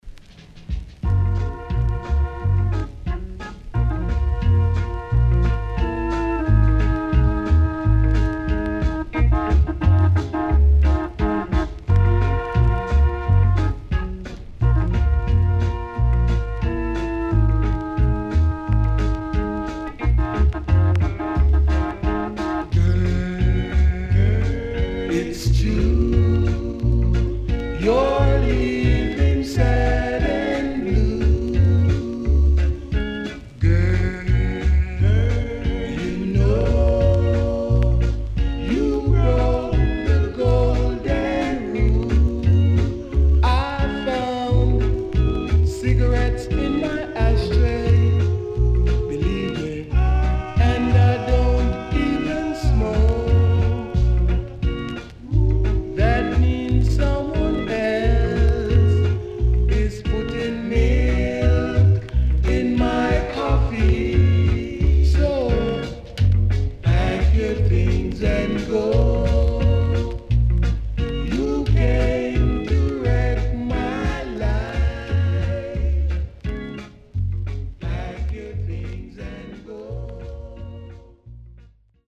哀愁漂うEarly Reggaeの名曲の数々を収録した名盤
SIDE B:全体的にチリプチノイズ入ります。